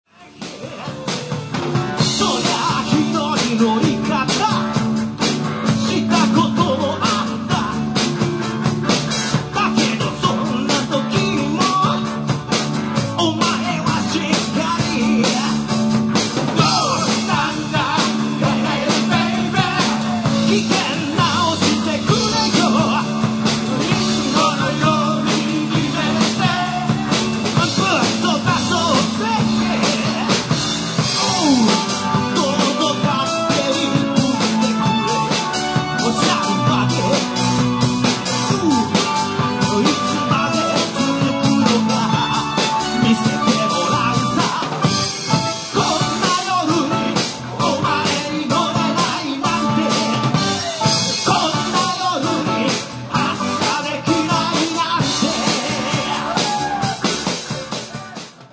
本番は、PAが弱いハコなので、演奏もコーラスも音量バランスはイマイチでしたが、内容的にはなかなかの出来となりました。
keyboard,chorus
drums
guitar
bass